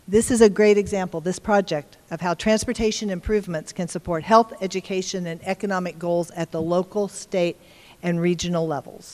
While the year-long closure of Kimball between North Manhattan and Denison Avenues is likely to draw plenty of consternation from drivers, it was the subject of praise Thursday at an event hosting KDOT Secretary Julie Lorenz.